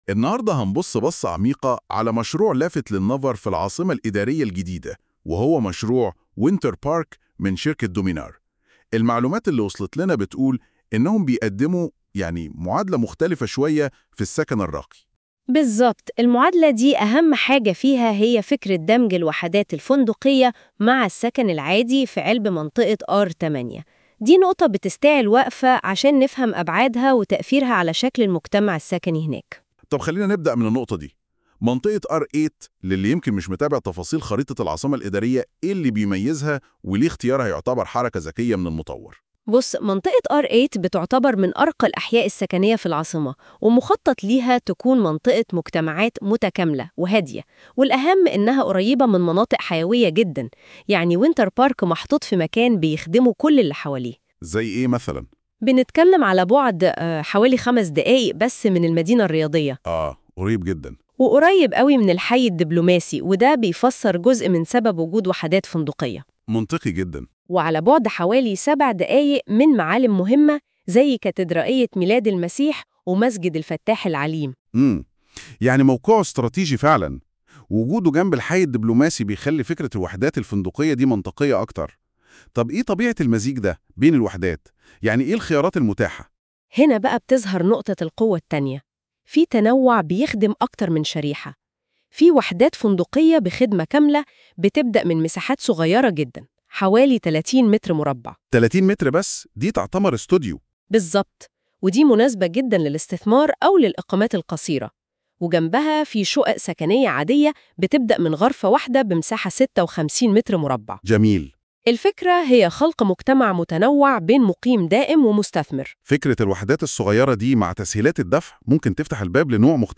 آراء العملاء الصوتية